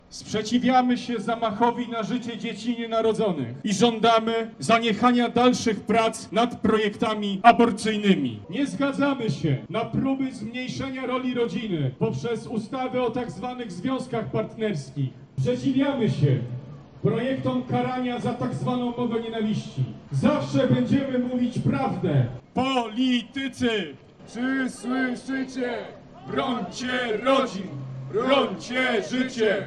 Manifestacja zatrzymała się przed sejmem, gdzie organizatorzy przedstawili swoje postulaty do posłów, między innymi o zaniechanie prac nad ustawami legalizującymi aborcję, o związkach partnerskich czy in-vitro.